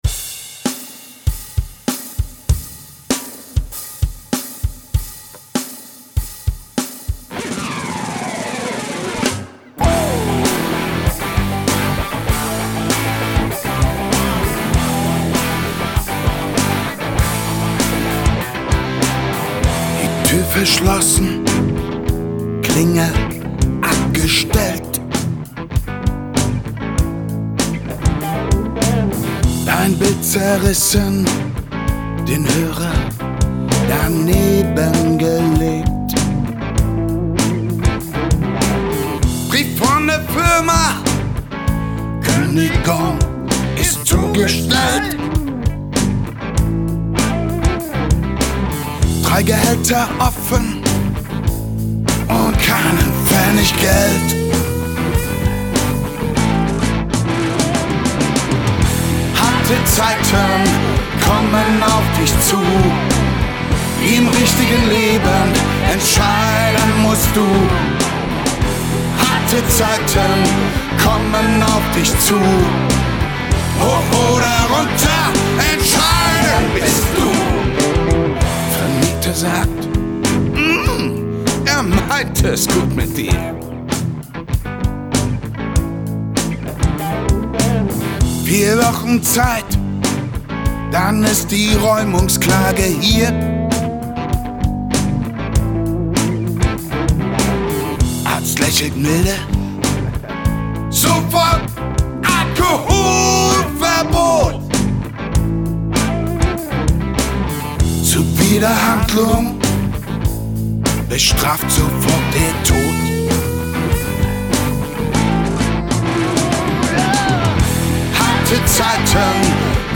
• Unplugged
• Blues
• Rockband